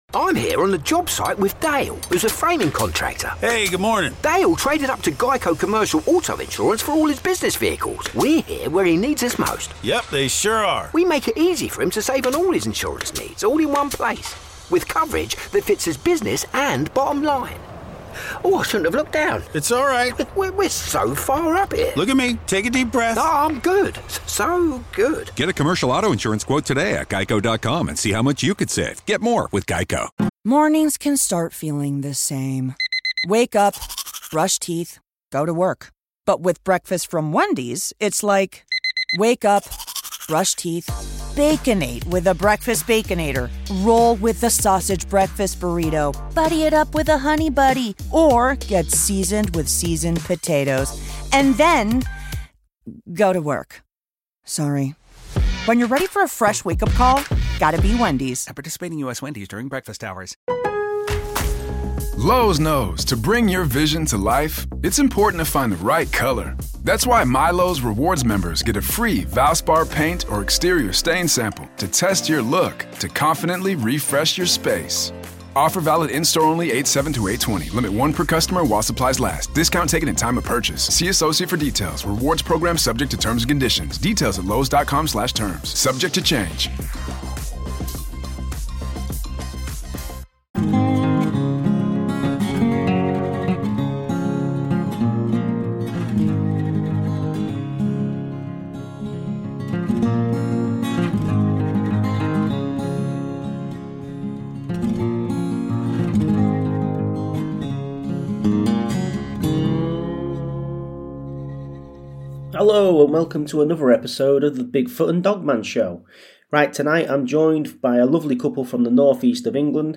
Tonight I am join by a lovely couple from the north-east of England, who had a strange encounter in Skegness